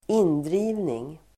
Ladda ner uttalet
Uttal: [²'in:dri:vning]
indrivning.mp3